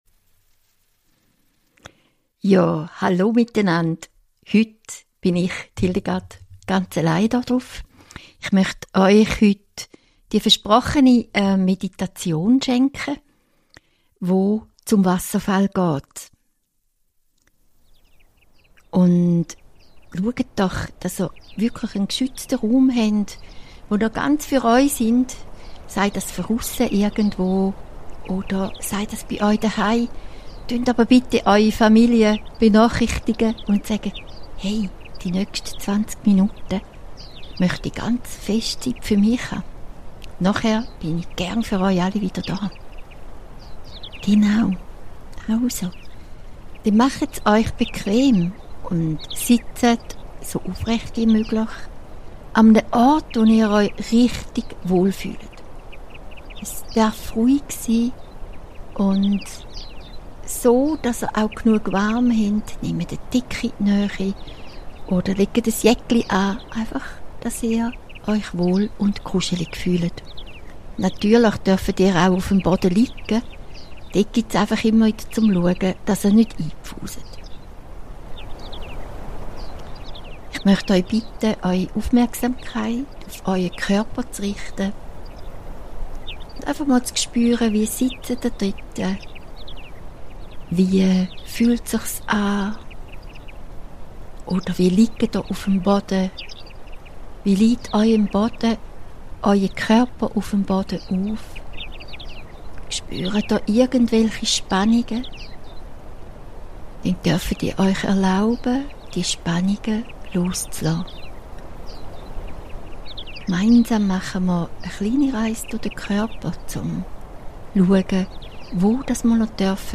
Bonus - Geführte Meditation: Reinigung & Loslassen am Wasserfall ~ Spirit of Life Podcast